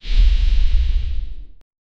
dashing Sfx that is like a wind
dashing-sfx-that-is-like-bb7xyzez.wav